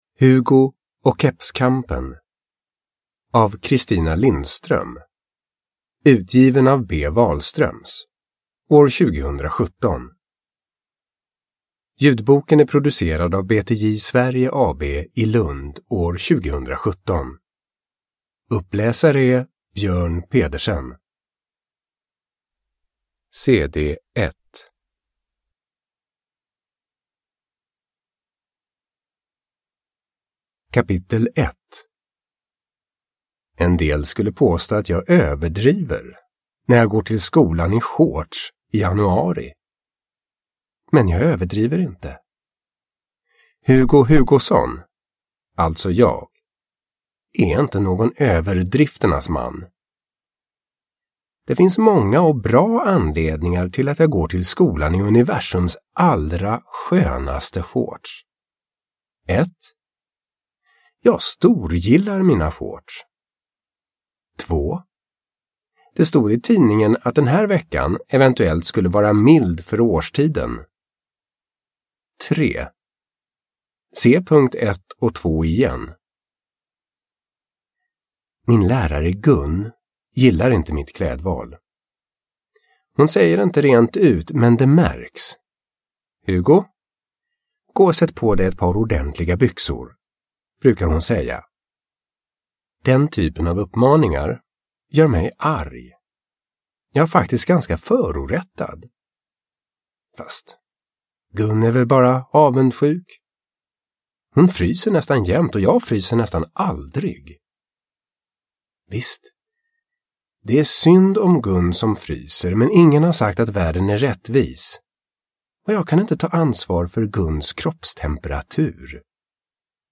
Hugo och kepskampen – Ljudbok – Laddas ner